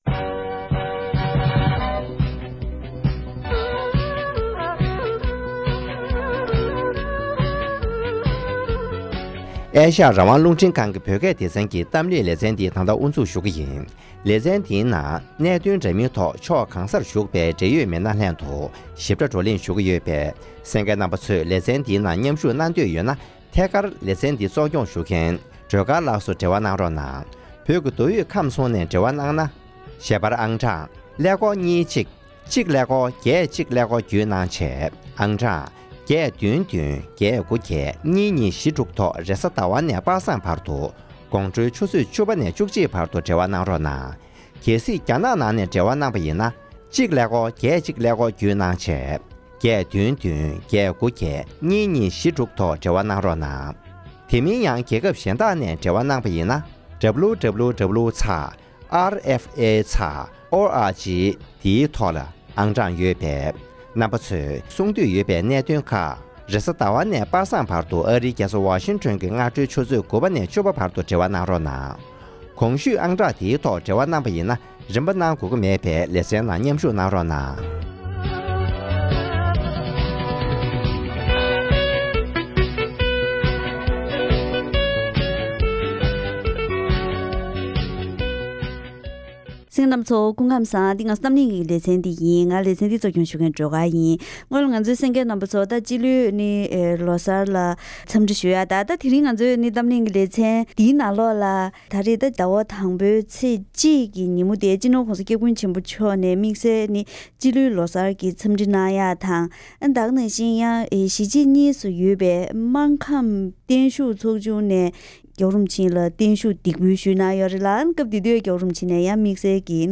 ༄༅། །ཐེངས་འདིའི་གཏམ་གླེང་གི་ལེ་ཚན་ནང་། སྤྱི་ལོའི་ལོ་གསར་དུ་བཞད་པའི་སྐབས་དེར་སྤྱི་ནོར་༸གོང་ས་༸སྐྱབས་མགོན་ཆེན་པོ་མཆོག་ལ་གཞིས་བྱེས་གཉིས་སུ་ཡོད་པའི་སྨར་ཁམས་པ་ཚོས་བརྟན་བཞུགས་འདེགས་འབུལ་ཞུས་ཡོད་པ་དང་། དེའི་སྐབས་༸གོང་ས་མཆོག་ནས་གནང་བའི་བཀའ་སློབ་ཟུར་བཏོན་ཞུས་ཏེ་ཞིབ་ཕྲའི་གནས་ཚུལ་ཁག་ངོ་སྤྲོད་ཞུས་པ་ཞིག་གསན་རོགས་གནང་།།